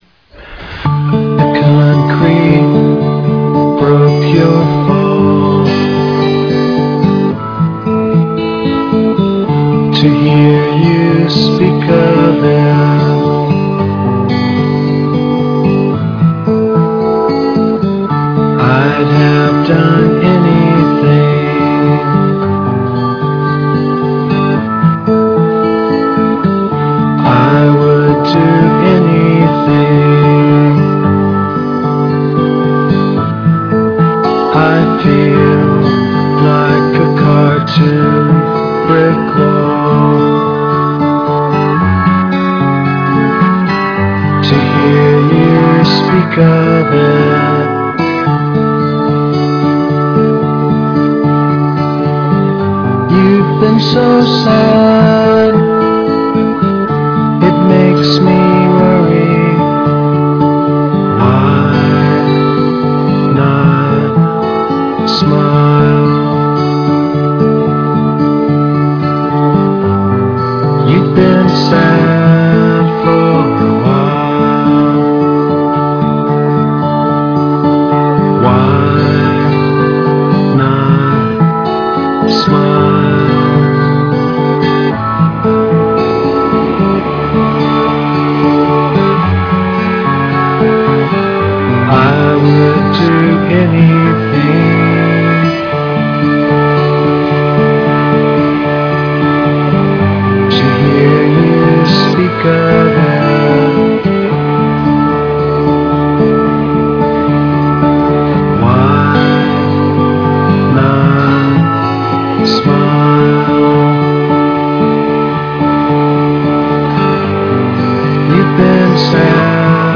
(Live Real Audio)